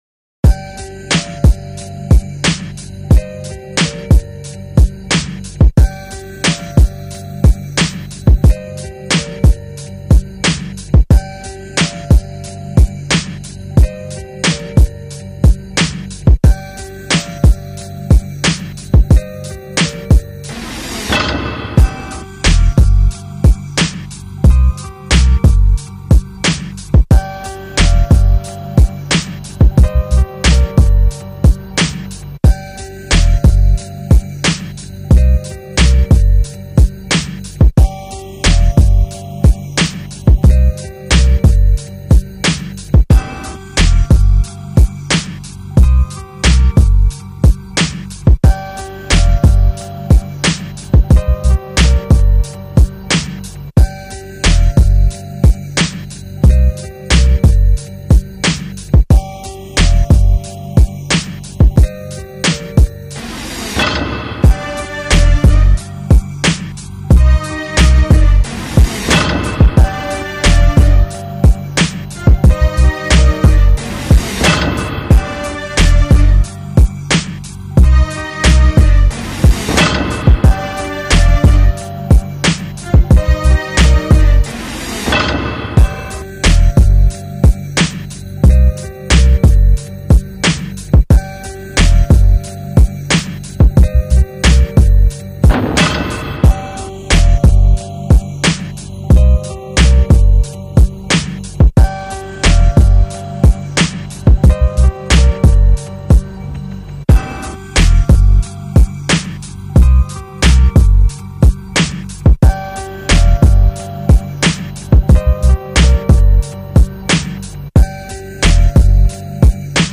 Krasser Beat, gut gemacht, leider keine Runde drauf.